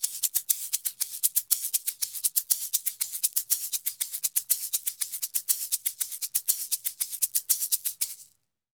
Eggs_ ST 120_4.wav